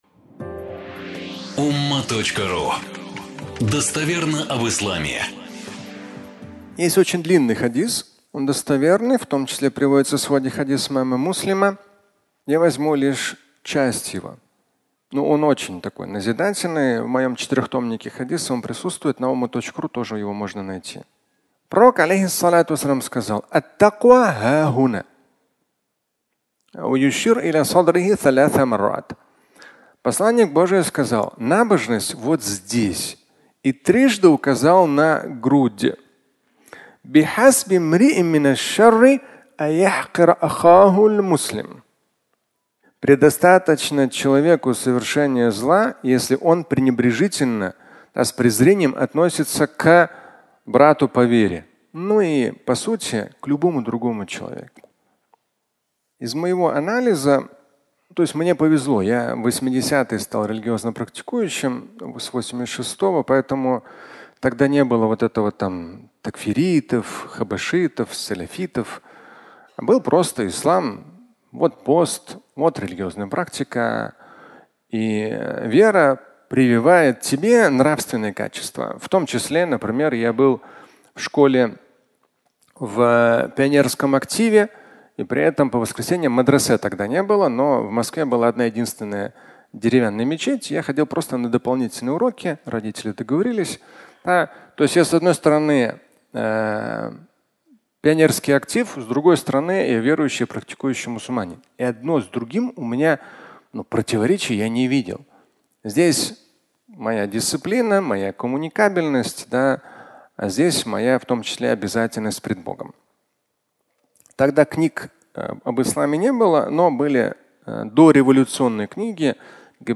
Идеологизированная секта (аудиолекция)